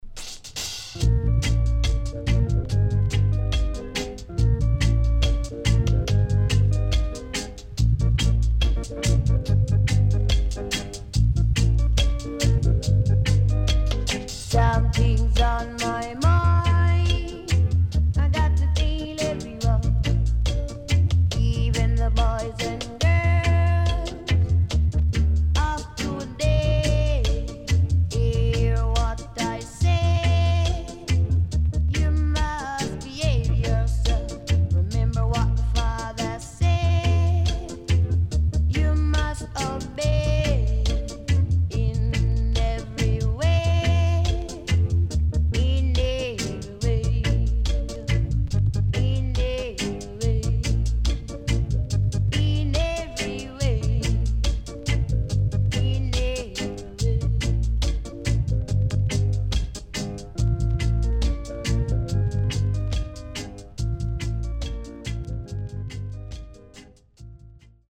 SIDE A:所々チリノイズがあり、少しプチノイズ入ります。
SIDE B:所々チリノイズがあり、少しプチノイズ入ります。